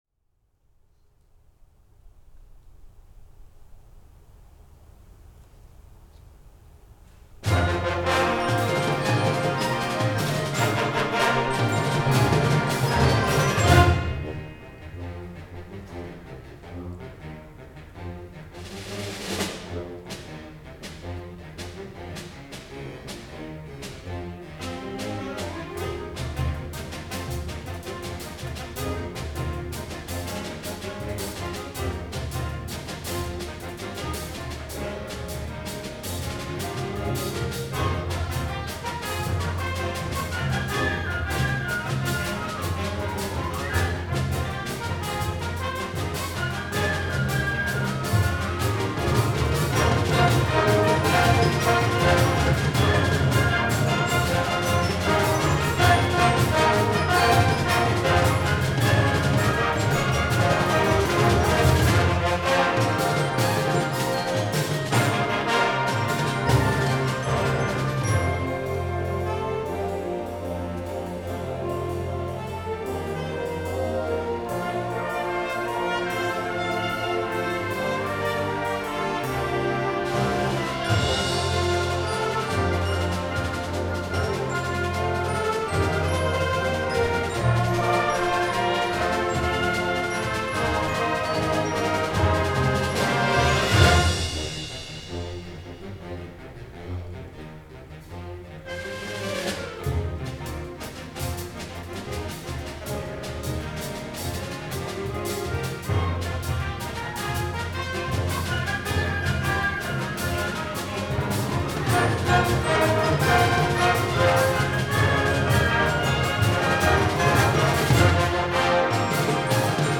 The LBB's winter concert was on Sunday March 6th 2022 at the Lexington High School in Lexington.